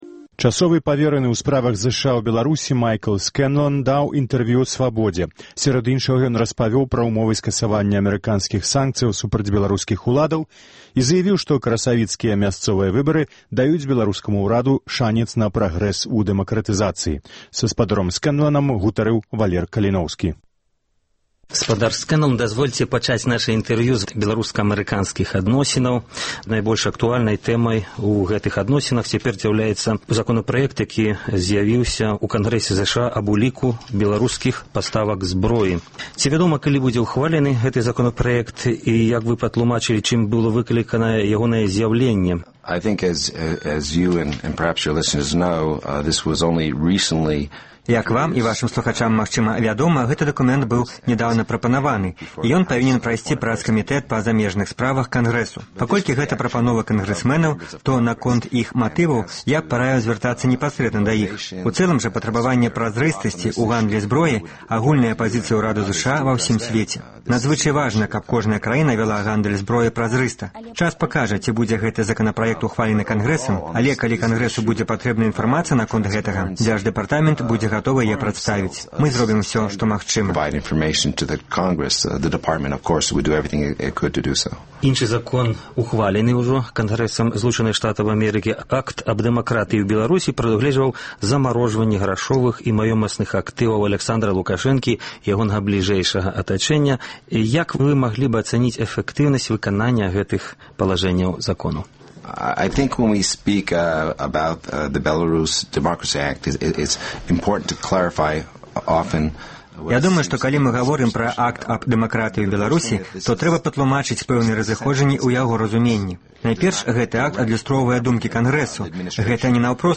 Часова павераны ў справах ЗША ў Беларусі Майкл Скэнлан даў інтэрвію «Свабодзе». Сярод іншага ён распавёў пра ўмовы скасаваньня амэрыканскіх санкцыяў супраць беларускіх уладаў і заявіў, што красавіцкія мясцовыя выбары даюць беларускаму ўраду шанец на прагрэс у дэмакратызацыі.